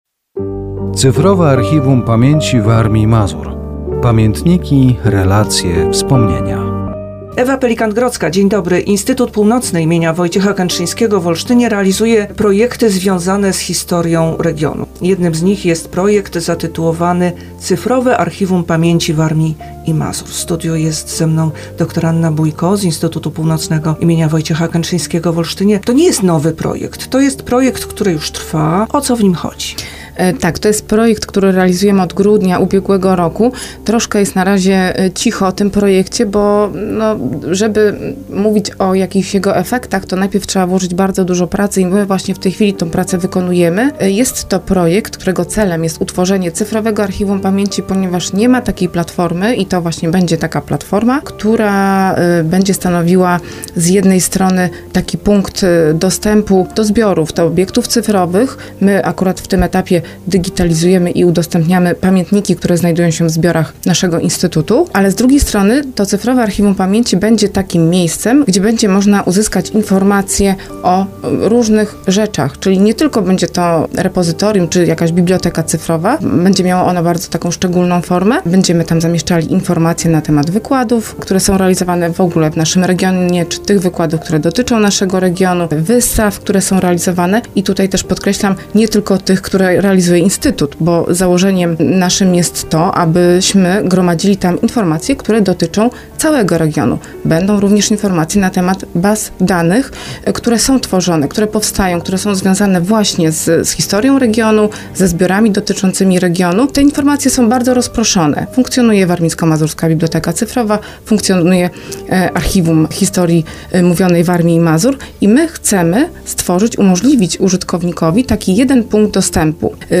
Audycja radiowa poświęcona projektowi "Cyfrowe Archiwum Pamięci Warmii i Mazur. Pamiętniki, relacje, wspomnienia".